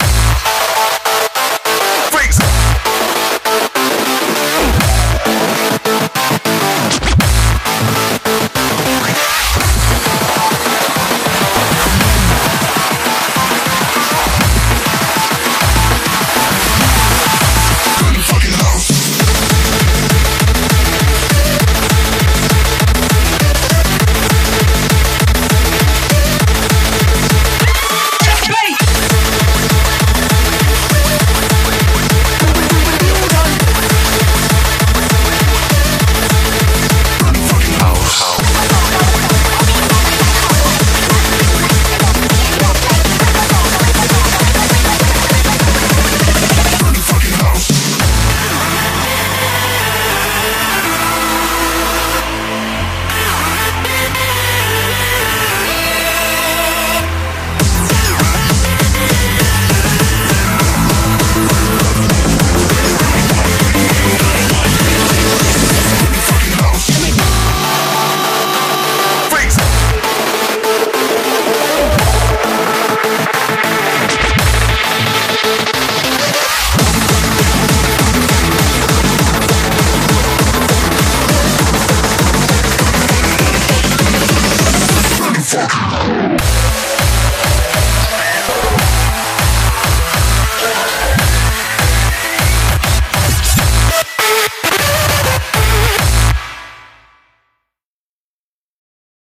BPM100-400
MP3 QualityMusic Cut